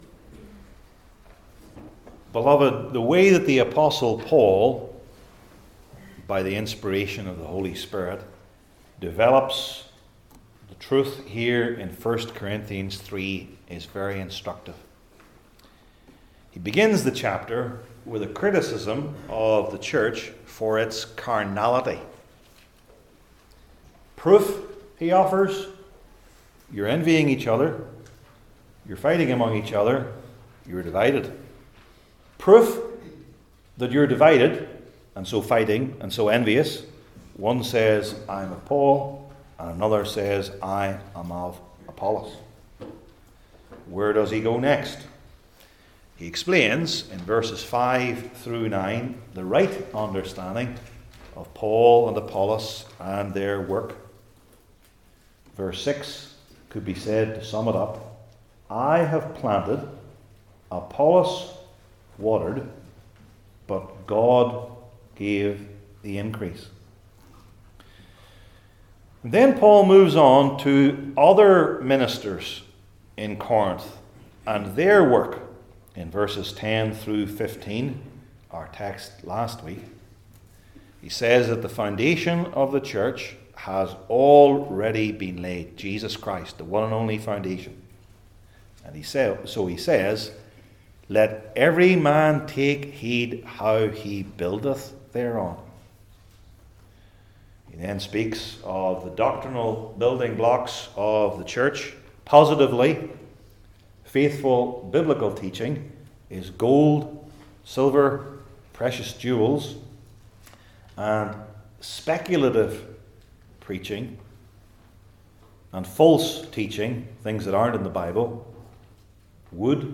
I Corinthians 3:16-17 Service Type: New Testament Sermon Series I. The Spiritual Temple II.